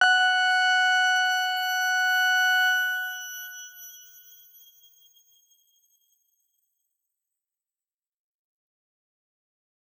X_Grain-F#5-mf.wav